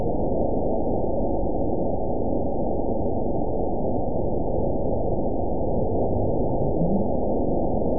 event 912146 date 03/19/22 time 09:10:24 GMT (3 years, 9 months ago) score 9.62 location TSS-AB05 detected by nrw target species NRW annotations +NRW Spectrogram: Frequency (kHz) vs. Time (s) audio not available .wav